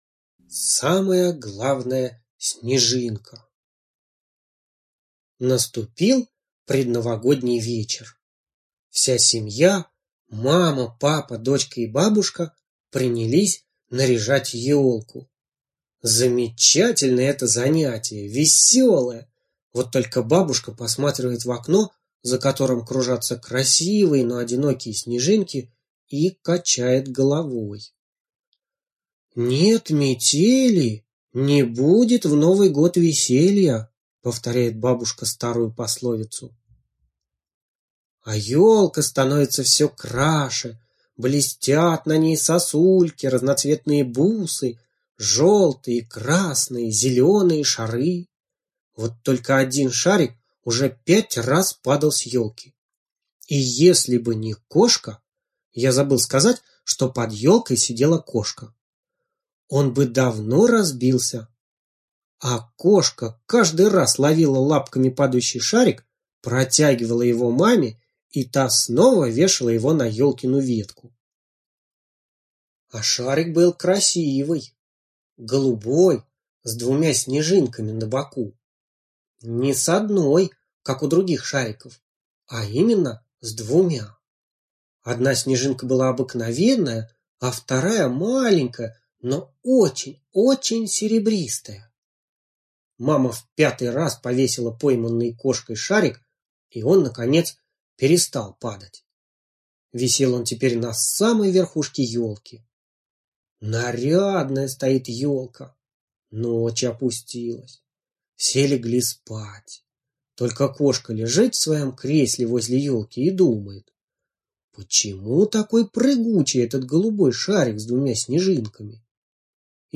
Слушайте Самая главная снежинка - аудиосказка Абрамцевой Н. Однажды в предновогоднюю ночь семья украшала елку, а один шарик несколько раз падал с елки.